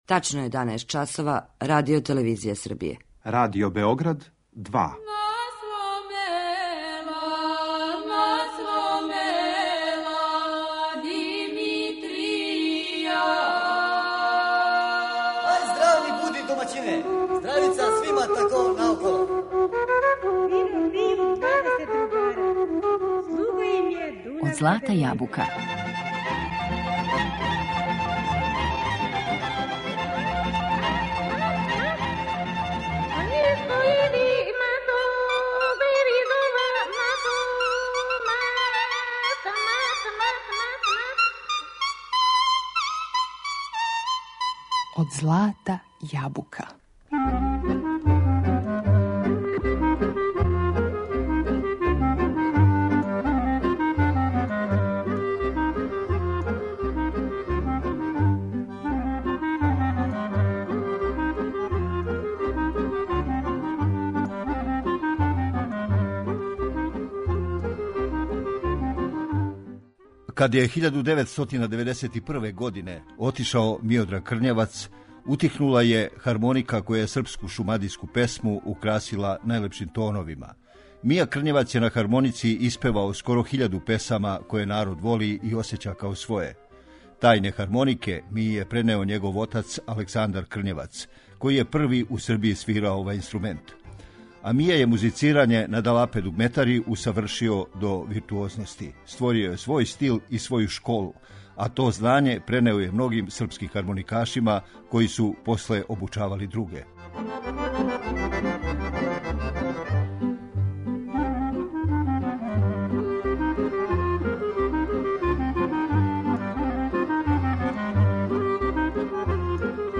Текст приредио и читао новинар